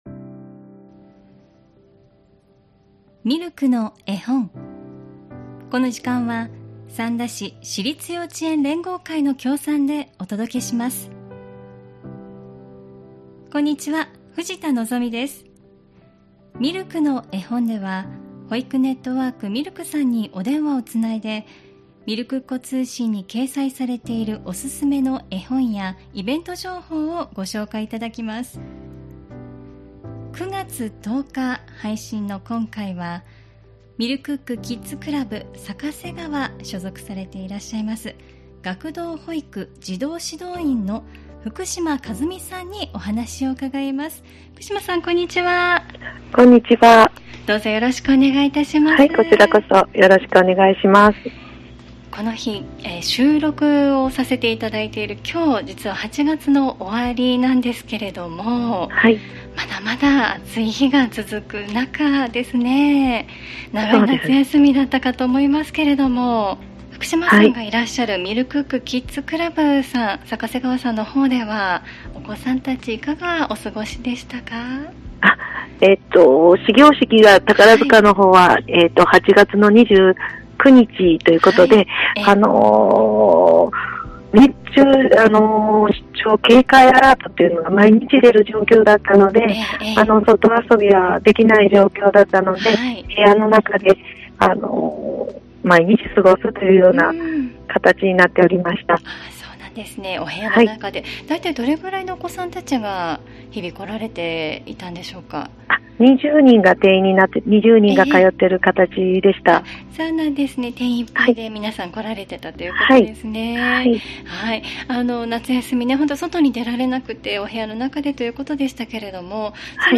保育ネットワーク・ミルクの保育士さんにお電話をつないで、みるくっ子通信に掲載されているおすすめの絵本やイベント・施設情報などお聞きします。